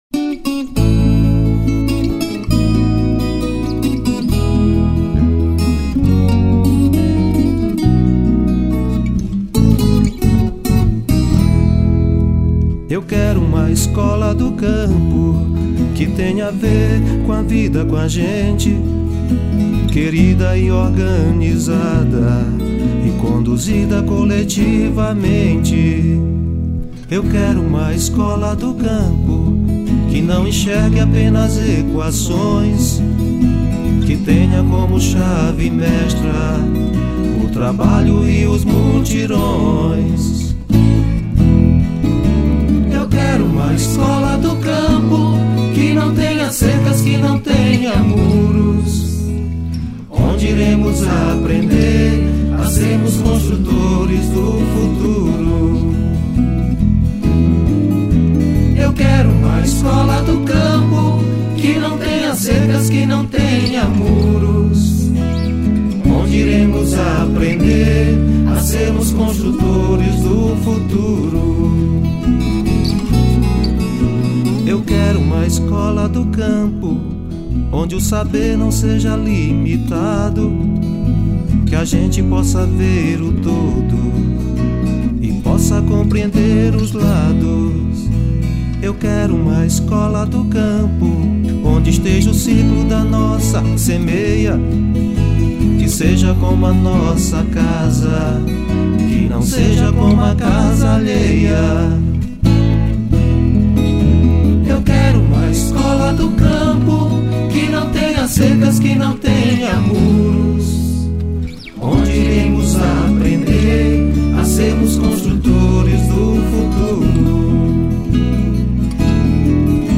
03:25:00   Ciranda